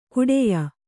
♪ kuḍeya